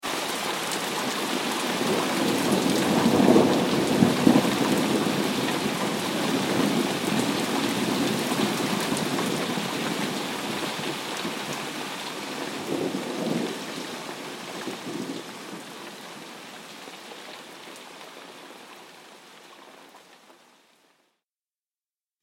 دانلود آهنگ طوفان 24 از افکت صوتی طبیعت و محیط
دانلود صدای طوفان 24 از ساعد نیوز با لینک مستقیم و کیفیت بالا
جلوه های صوتی